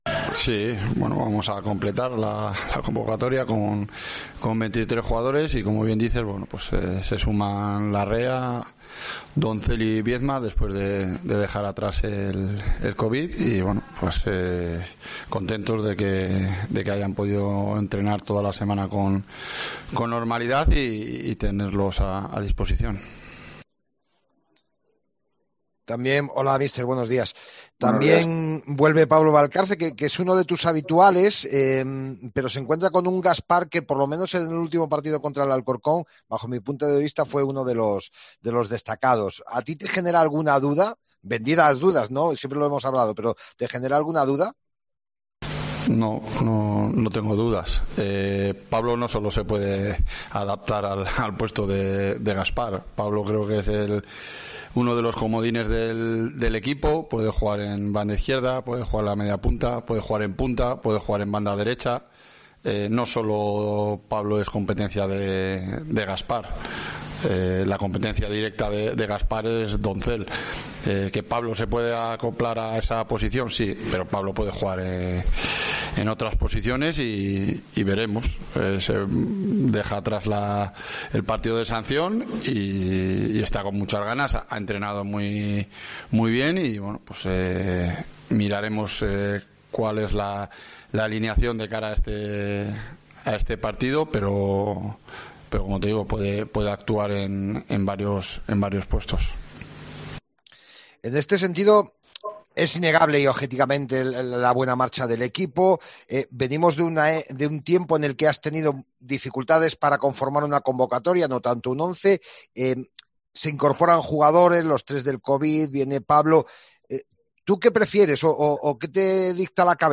AUDIO: Escucha aquí las palabras del entrenador de la Ponferradina